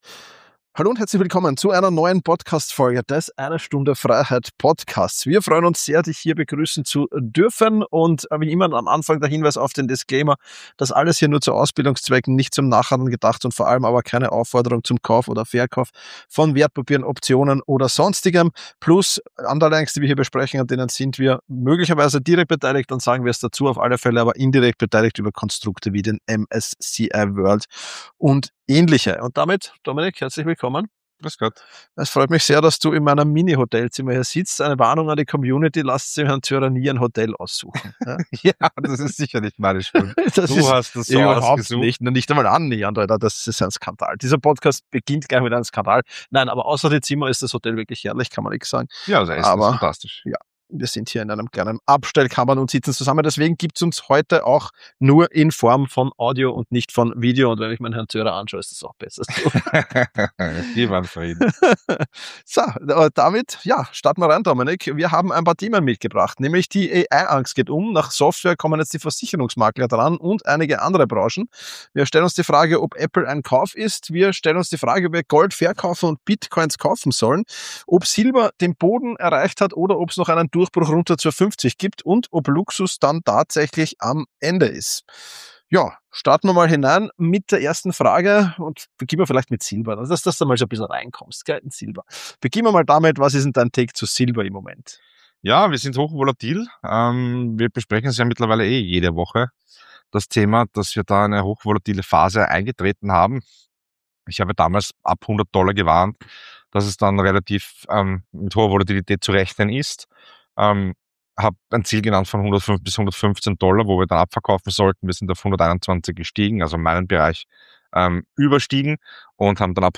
improvisiertes Podcast-Studio – eine Abstellkammer im Hotel